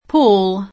Paul [fr. p0l; ingl.
p0oN; ted. p#ul] pers. m. (= Paolo) e cogn.